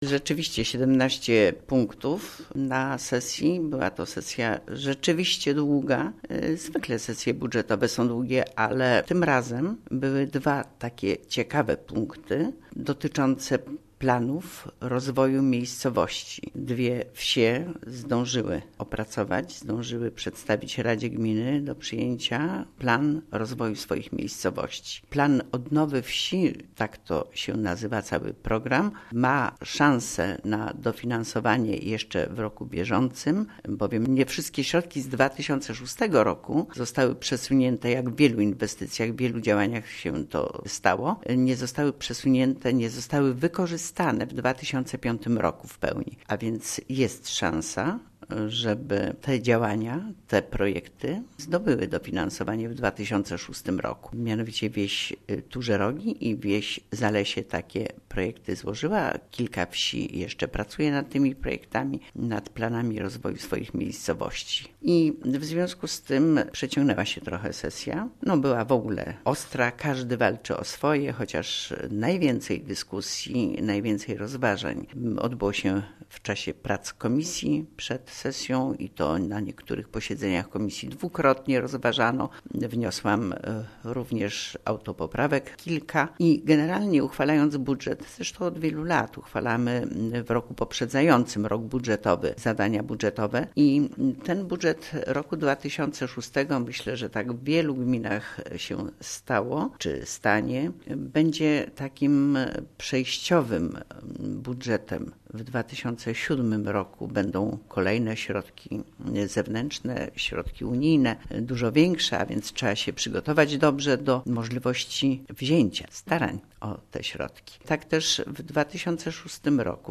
O tegorocznym budżecie Gminy Łuków, a także o innych punktach sesji informuje:
Wójt Gminy Łuków